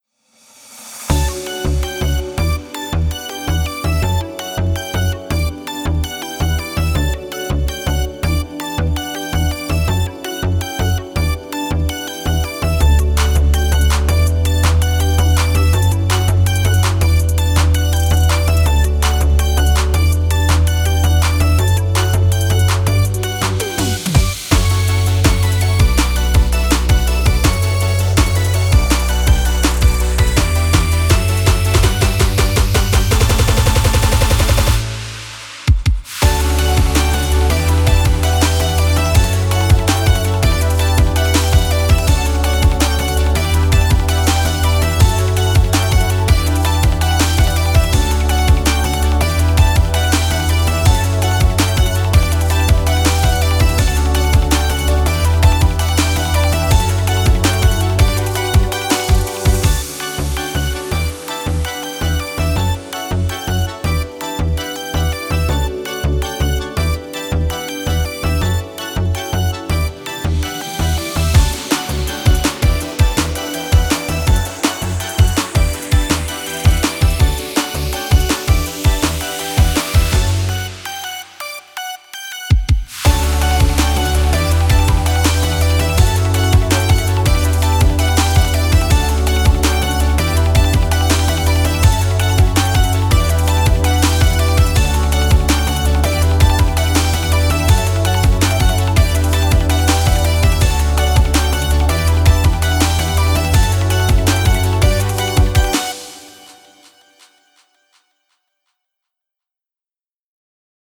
明るい/エンディング/かわいい/日常/ゆったり
【ループ版あり】かわいい雰囲気のゆったりした明るいBGMです。